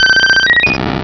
pokeemerald / sound / direct_sound_samples / cries / vaporeon.aif
-Replaced the Gen. 1 to 3 cries with BW2 rips.